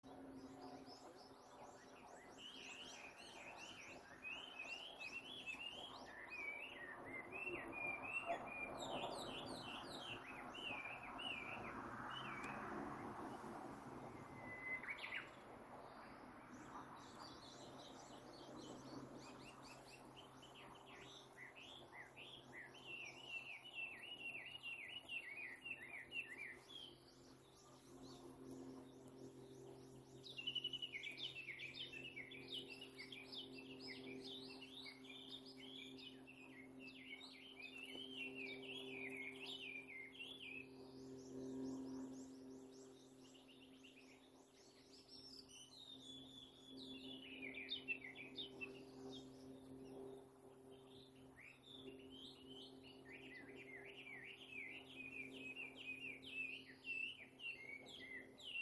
宿は、この湖の北の畔でしてん。
朝、夜明け6時前の明るい陽射しの中で、見えた富士山！
＊録音：　夜明けの賑やかな小鳥の鳴き声